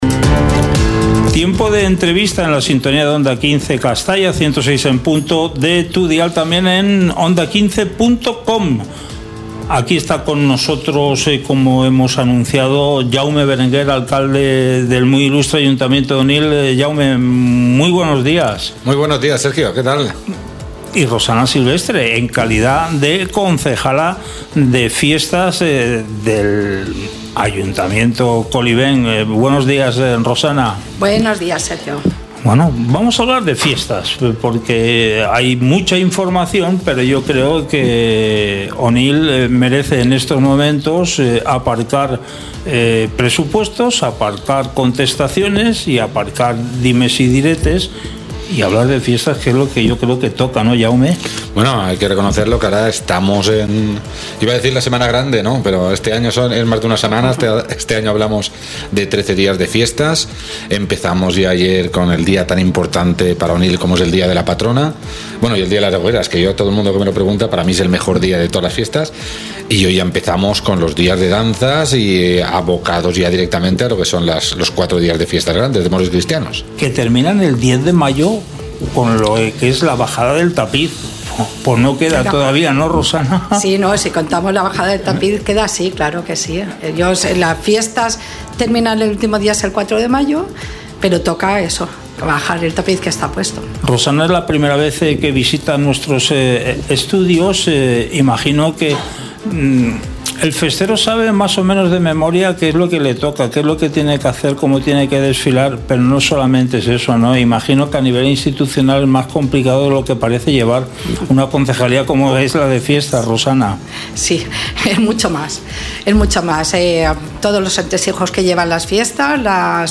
Hoy en nuestro Informativo, contamos con la presencia de Jaume Berenguer y Rosana Silvestre, Alcalde y Concejala de Fiestas del MI Ayuntamiento de Onil.
En esta entrevista, repasamos los distintos actos para las próximas Fiestas de Moros y Cristianos.